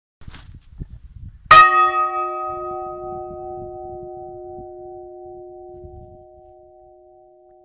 cloche (n°2) - Inventaire Général du Patrimoine Culturel